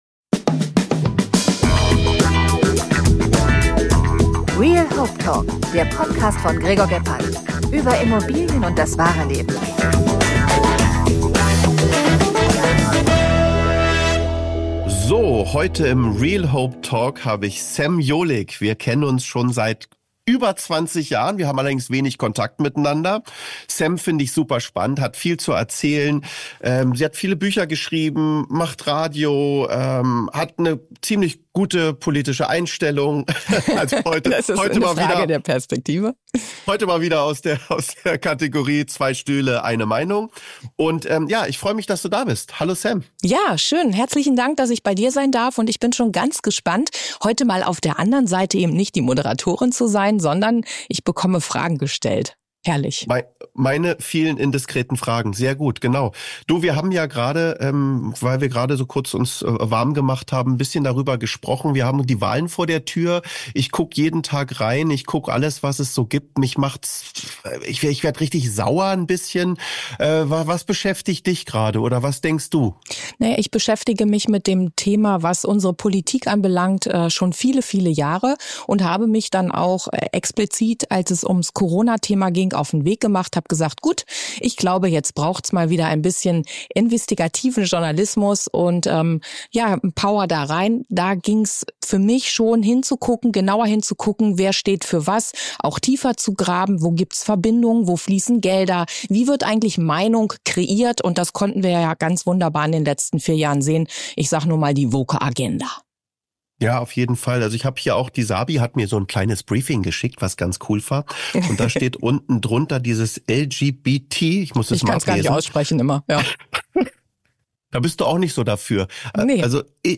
Ein Talk für Herz und Verstand!